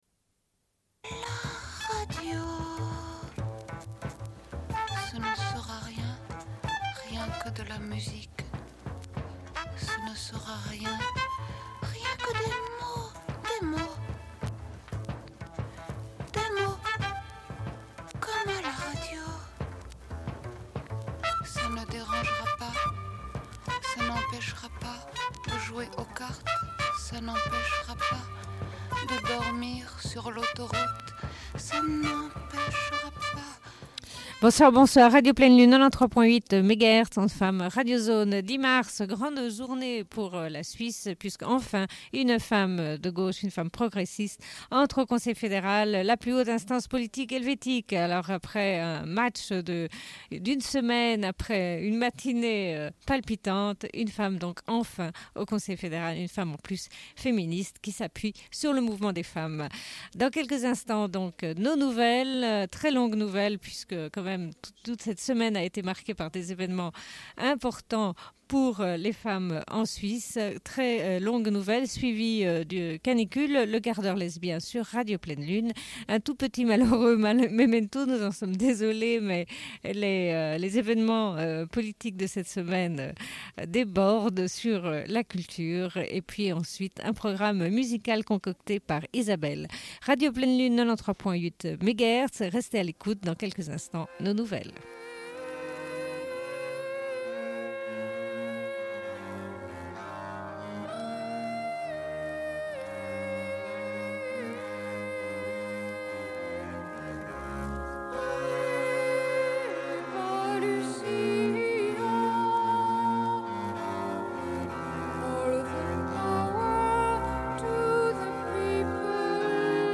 Bulletin d'information de Radio Pleine Lune du 10.03.1993 - Archives contestataires
Une cassette audio, face B29:07